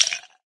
plasticice3.ogg